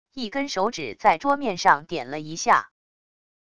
一根手指在桌面上点了一下wav音频